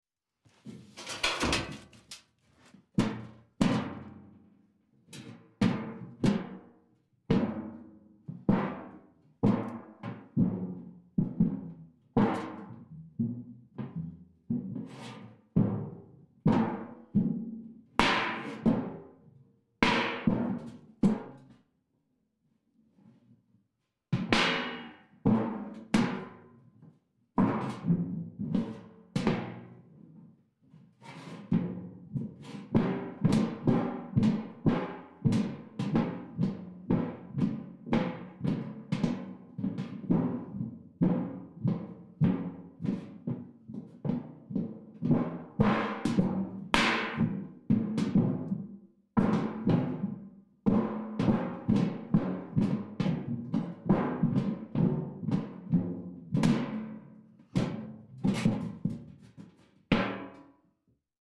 Звуки ползания
Звук ползания человека по железному листу